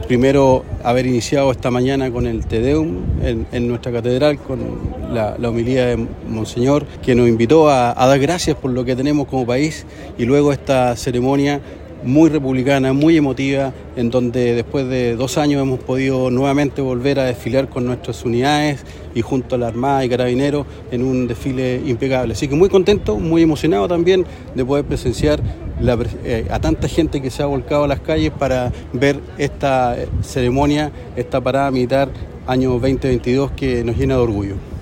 El general de División del Comando de Operaciones Terrestres, Christian Bolívar, calificó el desfile de impecable y se manifestó emocionado por la gran cantidad de gente que llegó a presenciarlo.